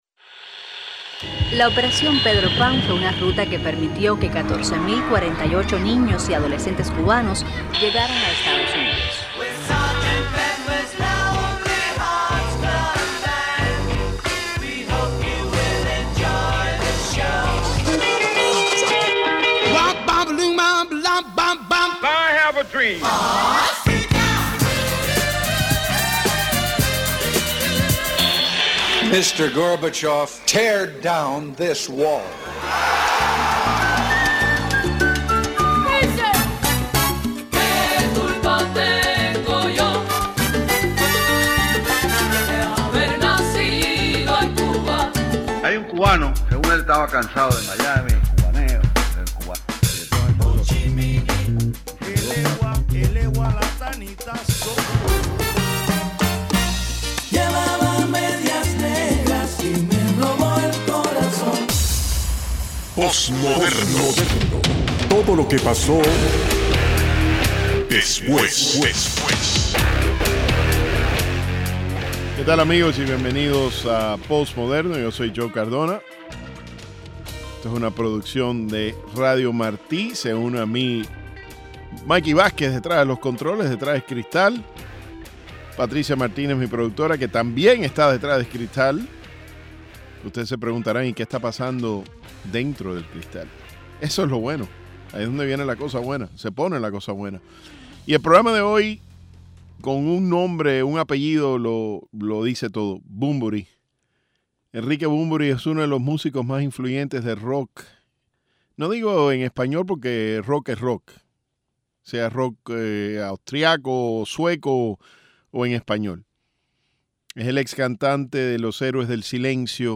banda de rock